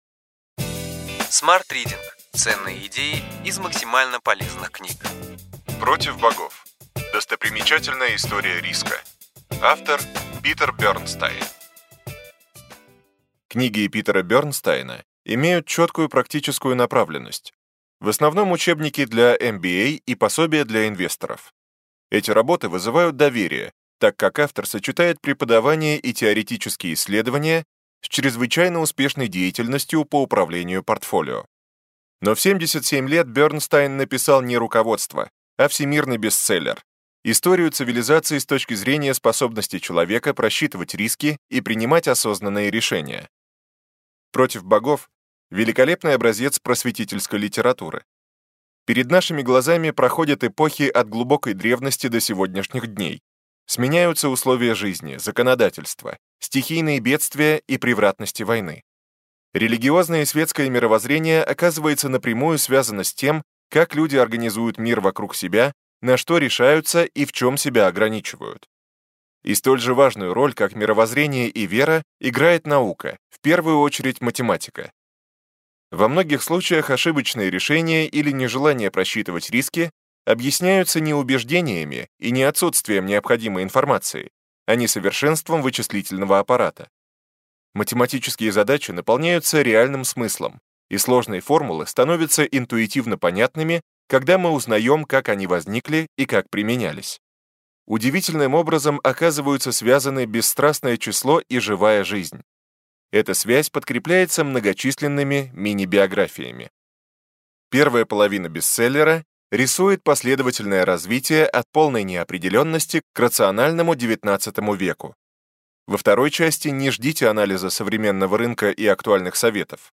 Аудиокнига Ключевые идеи книги: Против Богов: достопримечательная история риска.